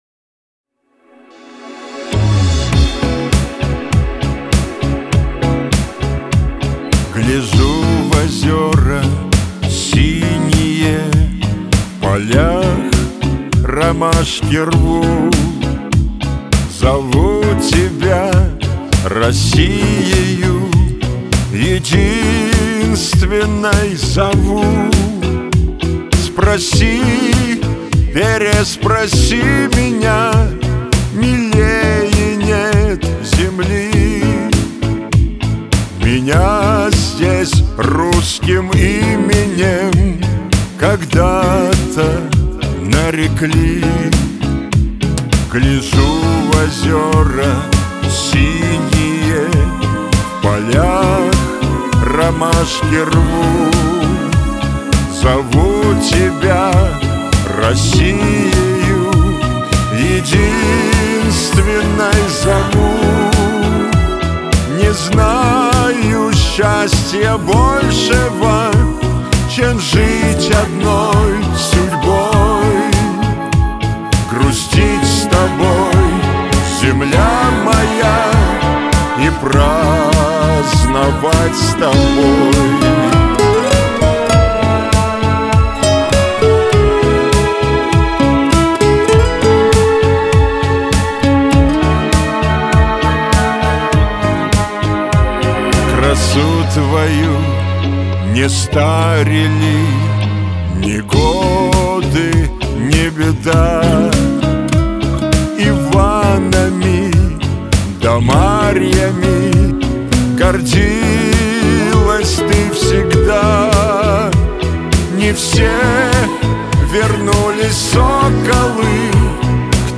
Лирика
Шансон